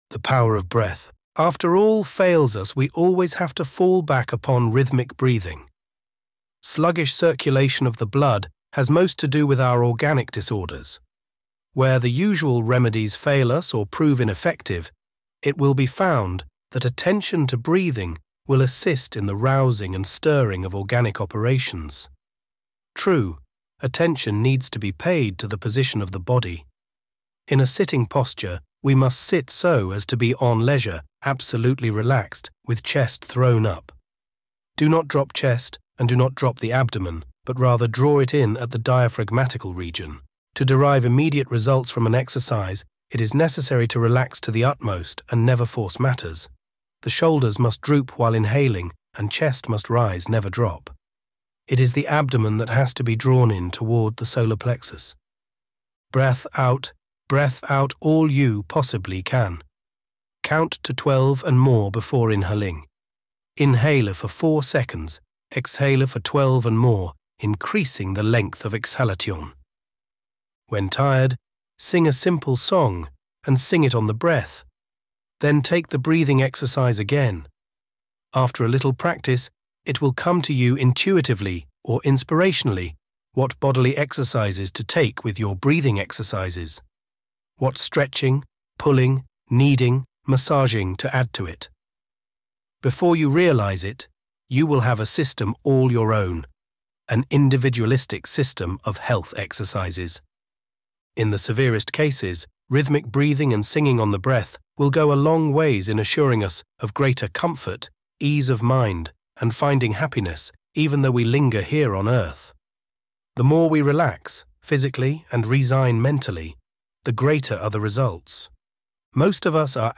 (spoken by Elevenlabs George)